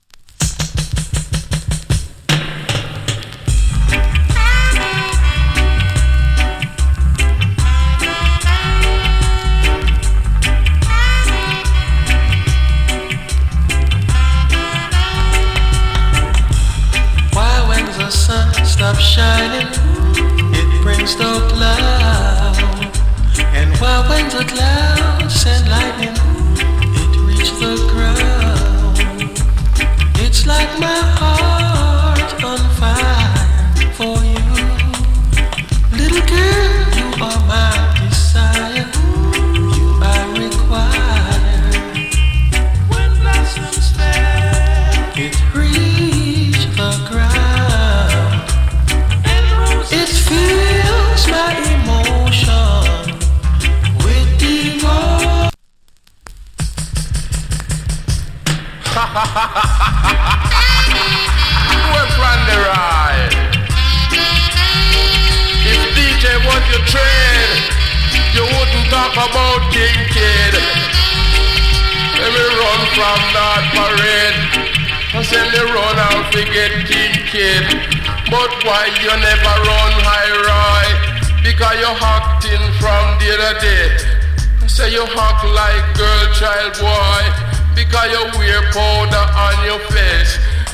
チリ、パチノイズわずかに有り。
チリ、パチノイズ少し有り。
＋ DEE-JAY CUT !!